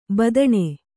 ♪ badaṇe